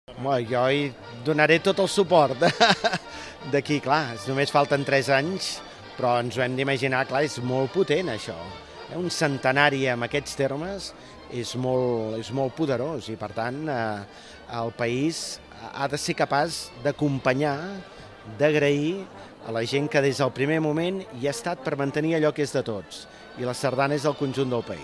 El president del Parlament va referir-se a la pròxima edició del centenari d’Aplec com un moment clau per a l’entitat i, a preguntes de Ràdio Calella TV, en to distès, va oferir-se a donar-hi suport.